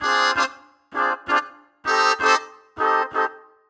Index of /musicradar/gangster-sting-samples/130bpm Loops
GS_MuteHorn_130-DA.wav